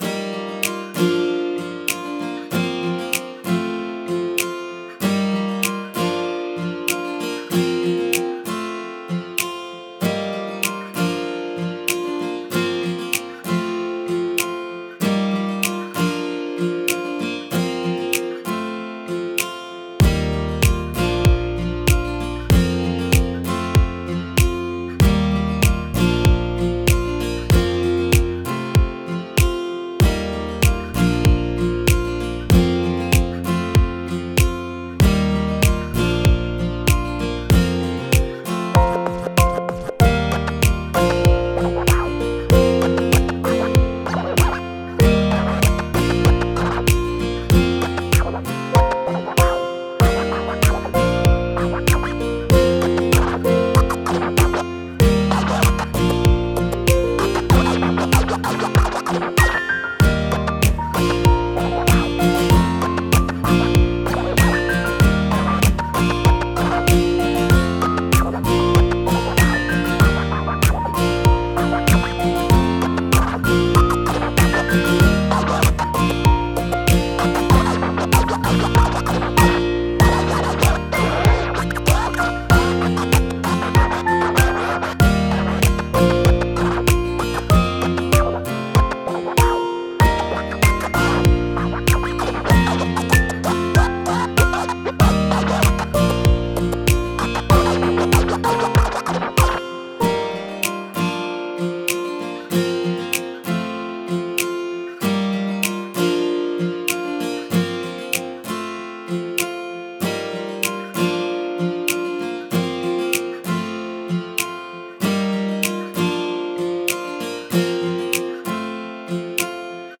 アコギのリフが繰り返される中、ターンテーブルによるスクラッチ音がキュッキュなる曲です。